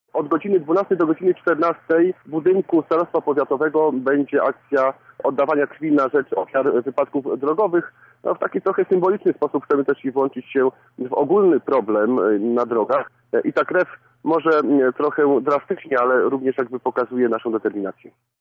To gest, którym chcemy pokazać naszą determinację– dodaje Żukowski: